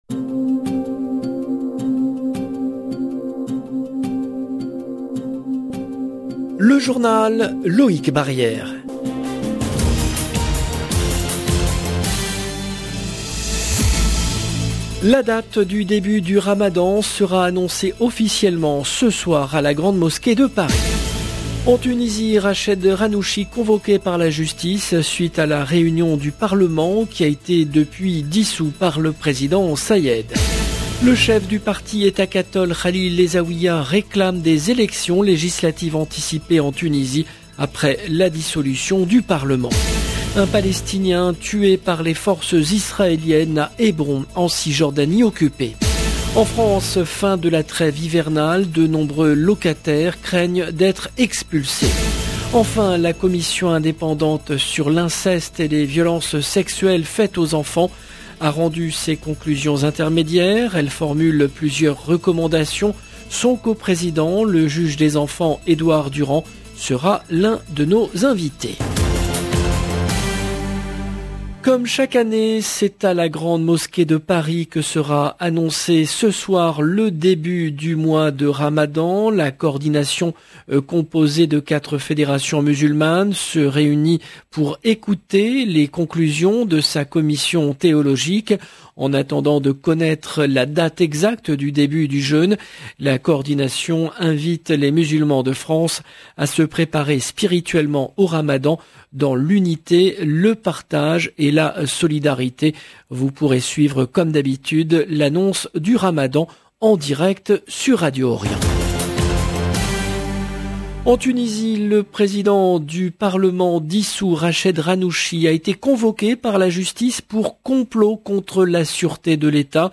Son co président, le juge des enfants Edouard Durand, sera l’un de nos invités. 0:00 18 min 32 sec